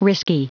Prononciation du mot risky en anglais (fichier audio)
Prononciation du mot : risky